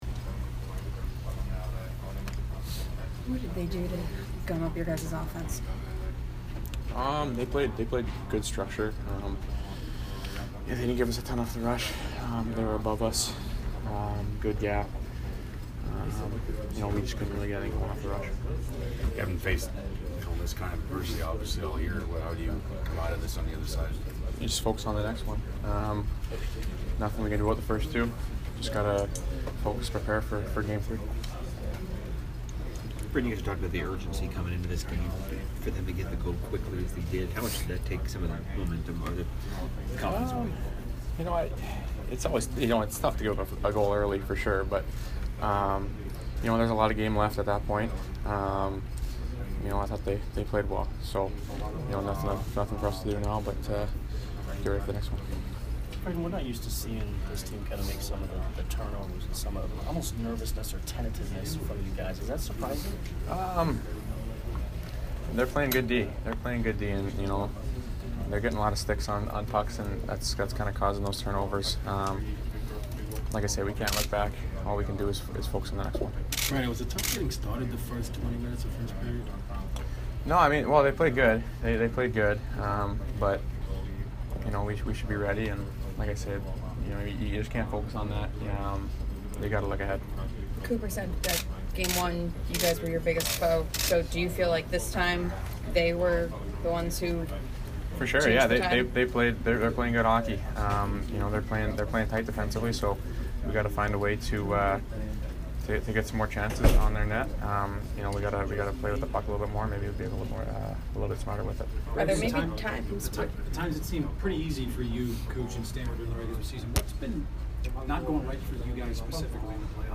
Brayden Point post-game 4/12